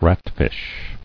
[rat·fish]